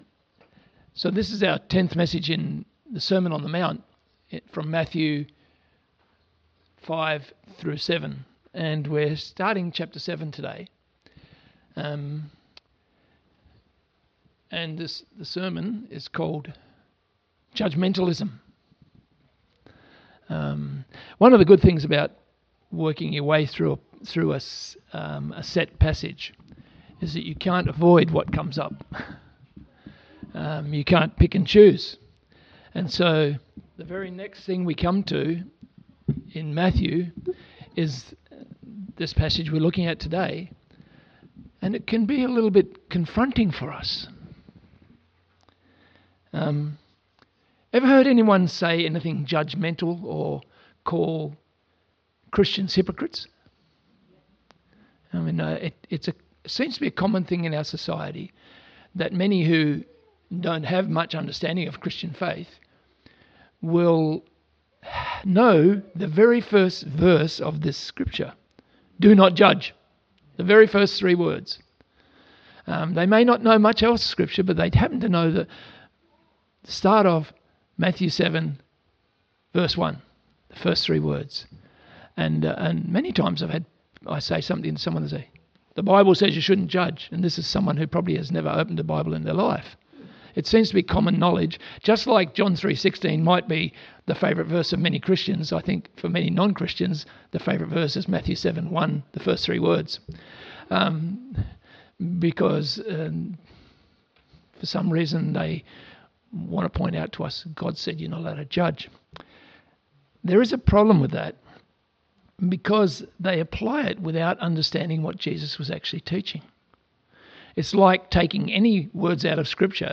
Series: Sermon on the Mount